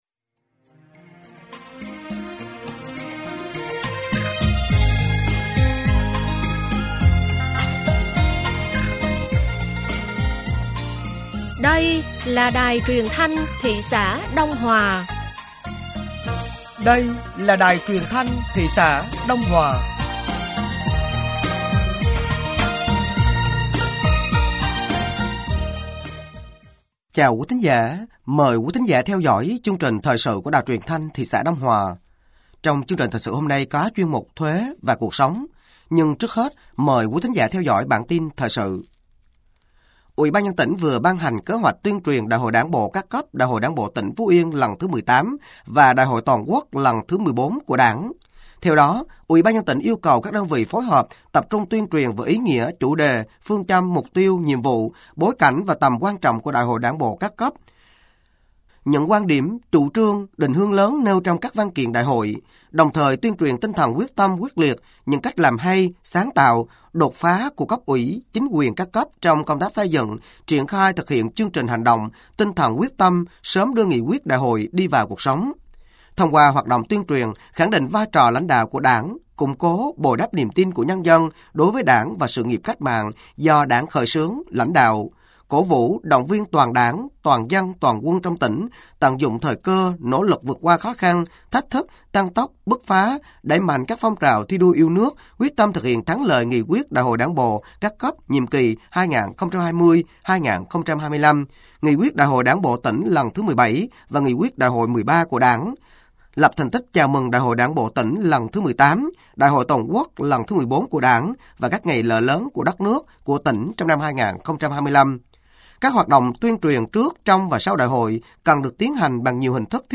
Thời sự tối ngày 20 và sáng ngày 21 tháng 02 nămn 2025